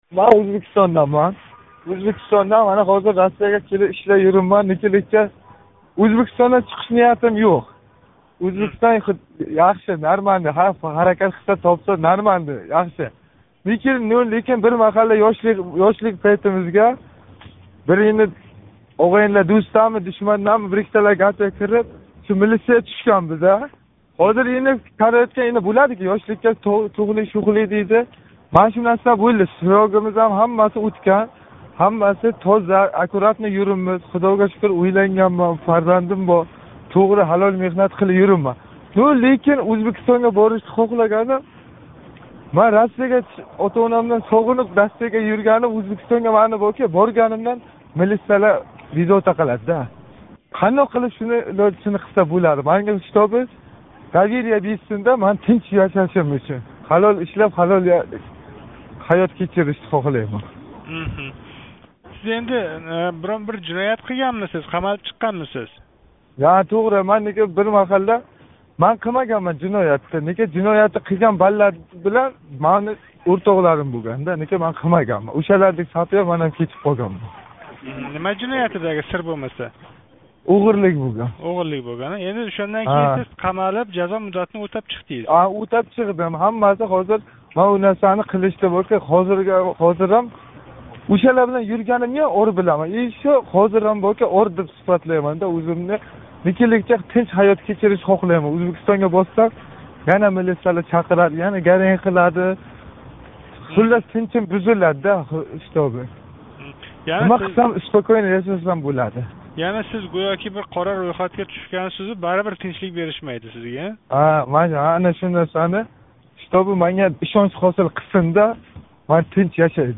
Қўшработлик тингловчи билан суҳбат